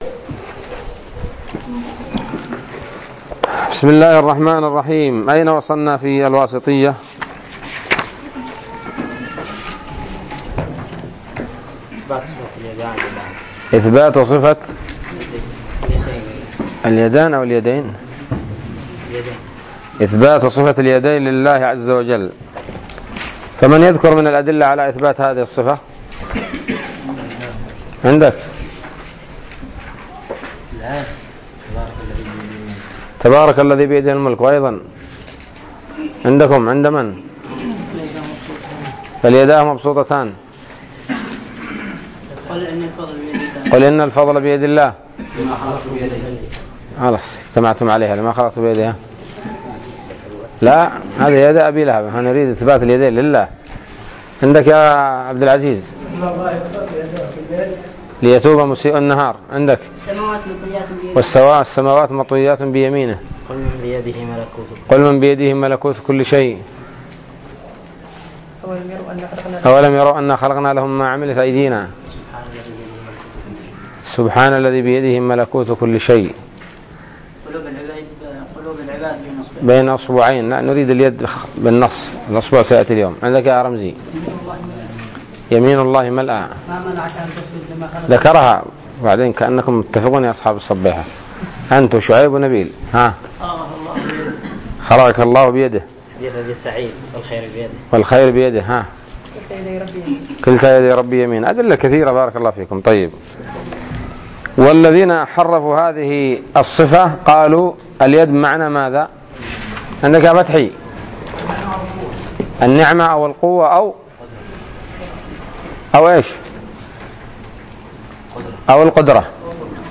الدرس الواحد والستون من شرح العقيدة الواسطية